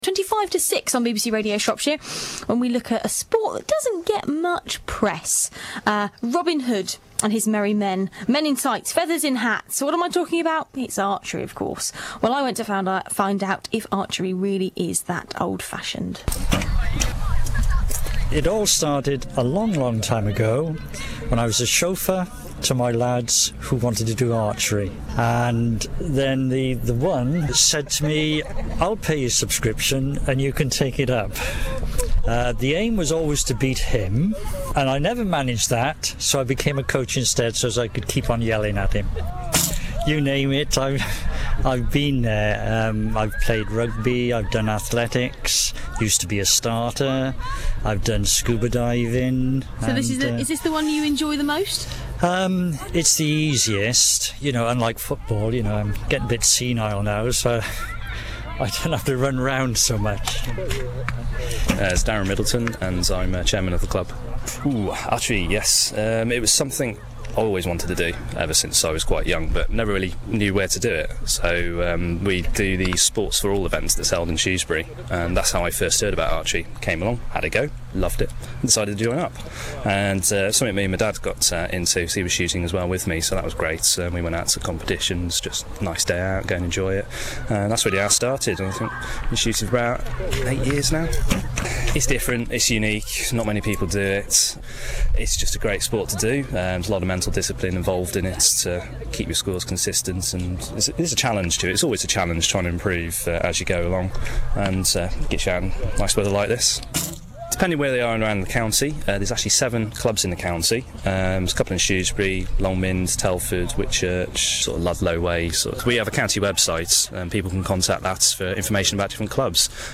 Archery on BBC Radio Shropshire (4MB mp3 file)